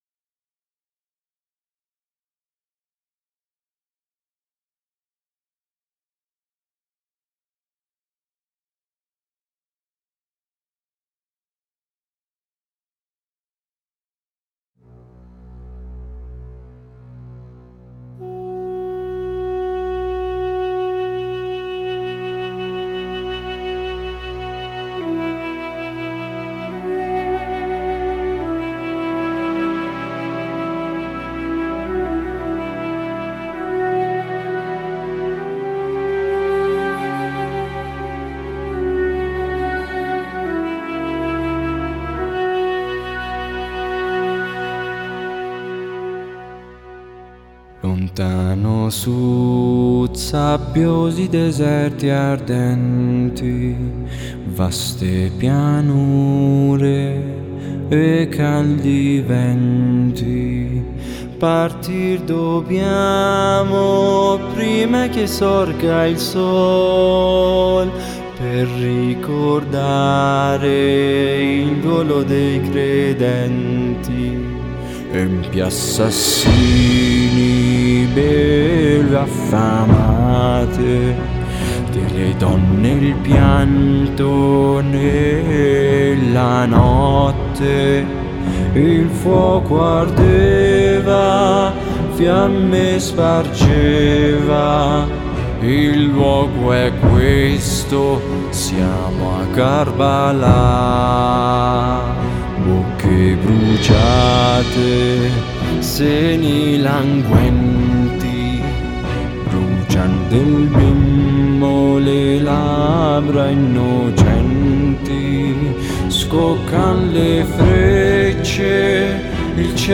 ترانه شیعی